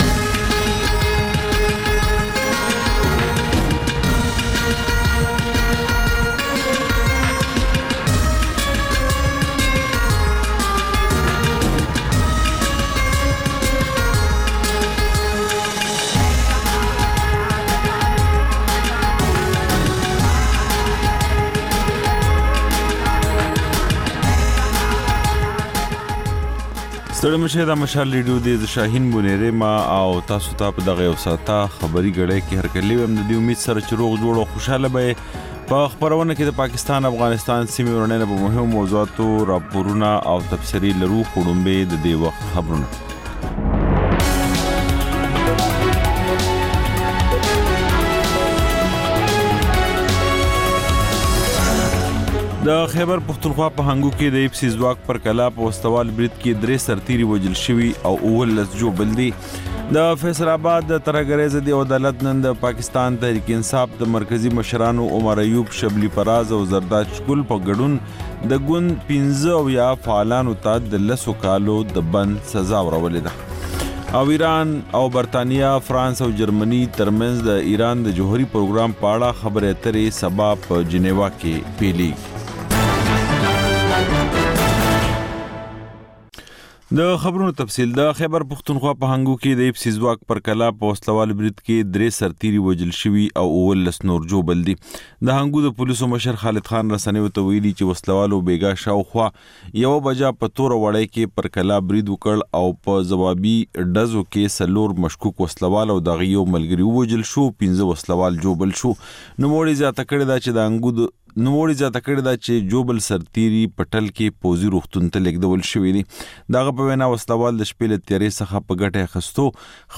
دویمه خبري ګړۍ تکرار